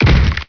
gibheavy2.wav